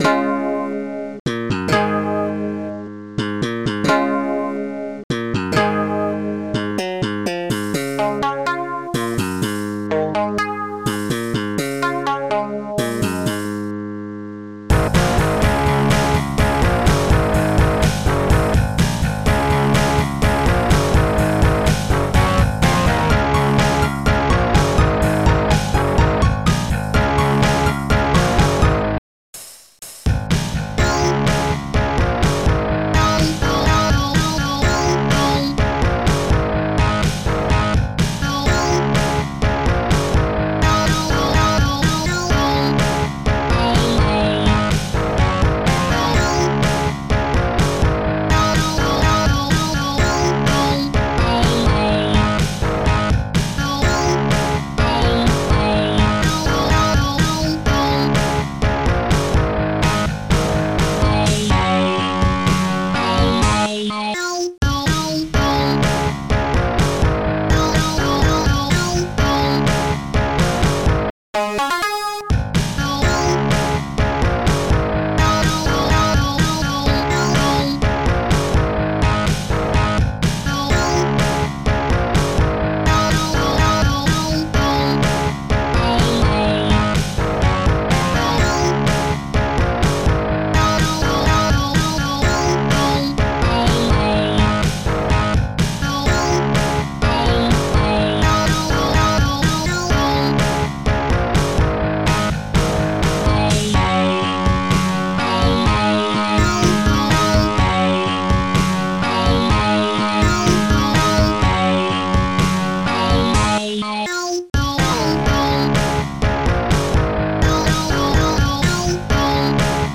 Protracker and family
acoustic guitar
bass
power chord
crash cymbal
snare
bass drum
piano
vocal
tom
converted to MOD by